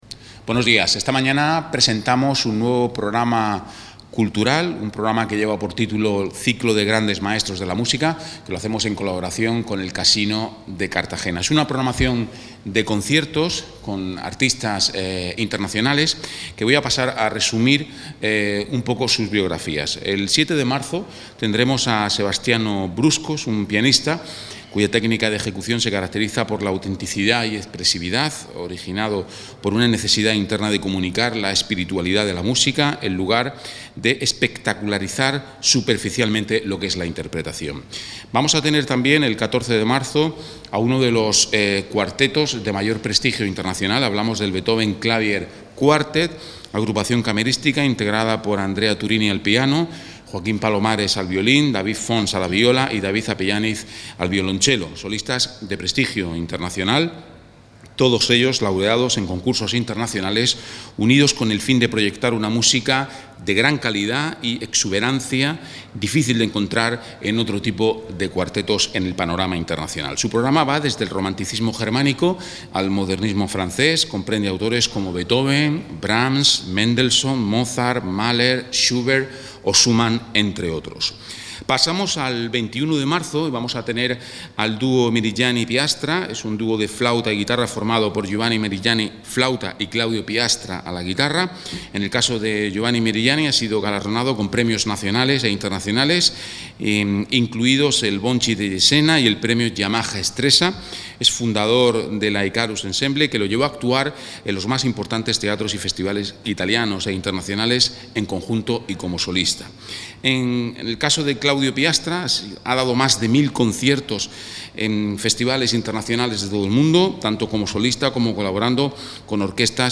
El ciclo ha sido presentado el miércoles, 15 de febrero, por el delegado del área de cultura Carlos Piñana